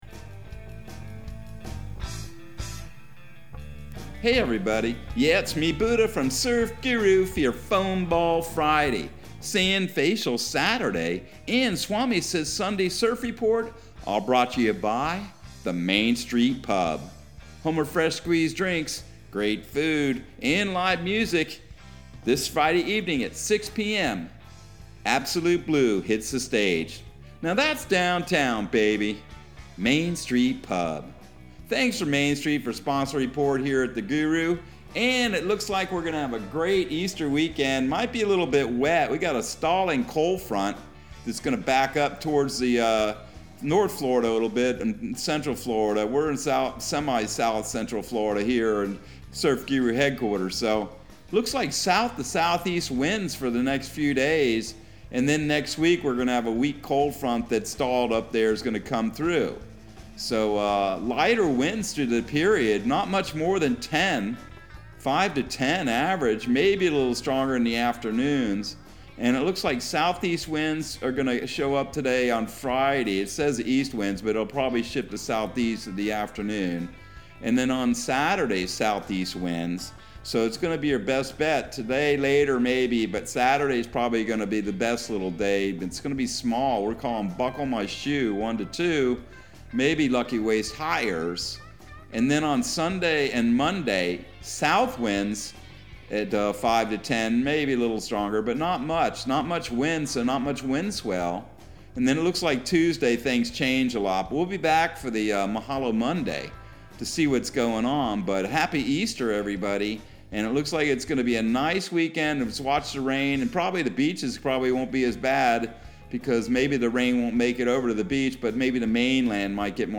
Surf Guru Surf Report and Forecast 04/15/2022 Audio surf report and surf forecast on April 15 for Central Florida and the Southeast.